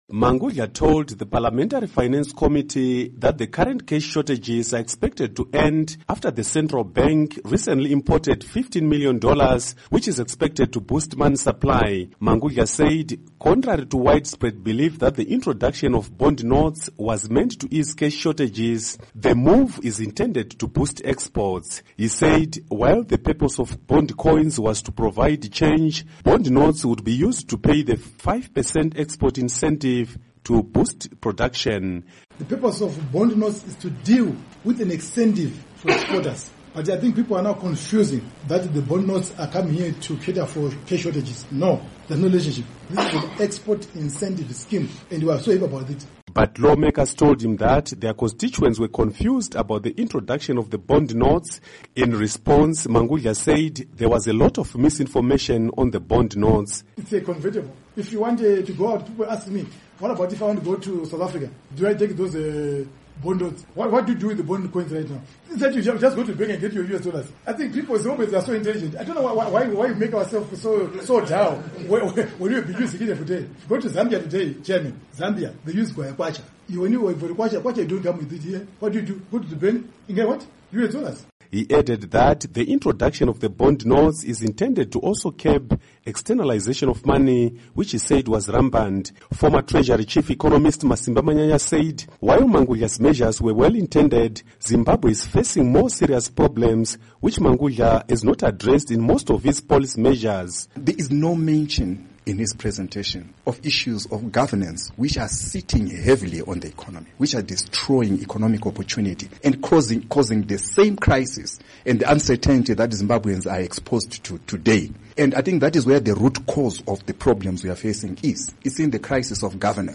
Report on Central Bank Governor